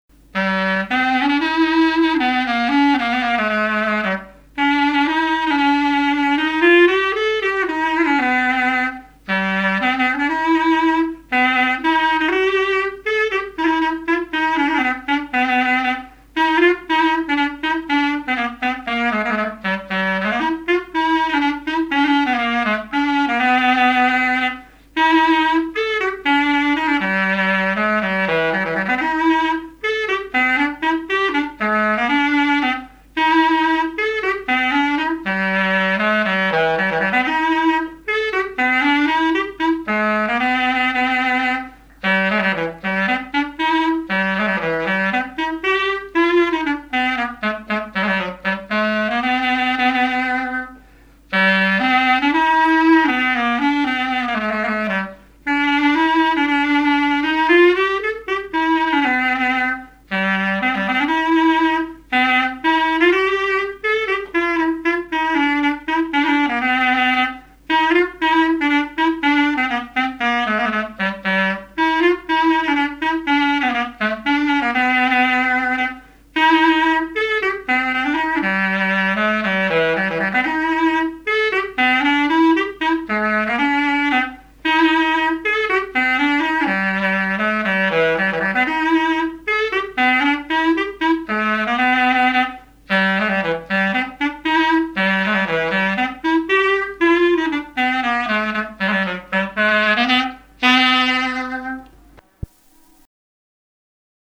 Marche nuptiale
Usage d'après l'informateur circonstance : fiançaille, noce ;
Pièce musicale inédite